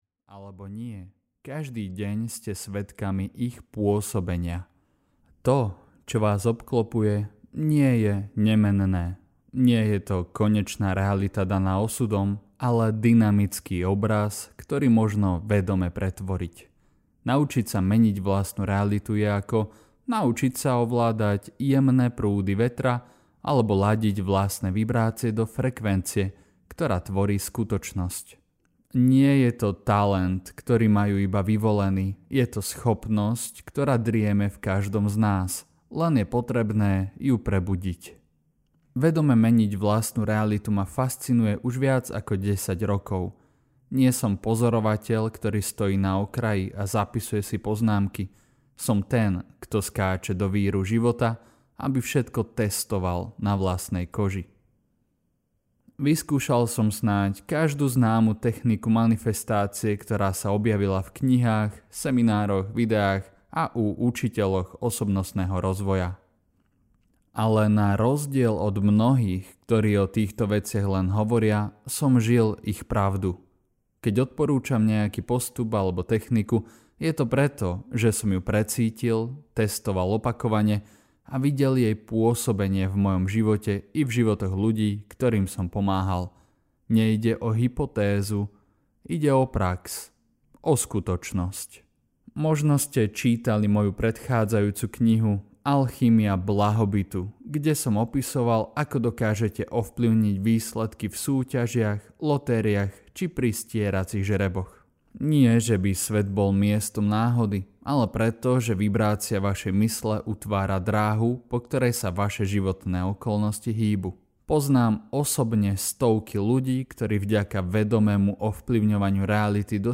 Tajné Zákony Reality audiokniha
Ukázka z knihy
tajne-zakony-reality-audiokniha